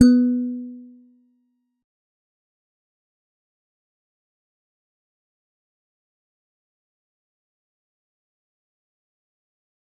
G_Musicbox-B3-mf.wav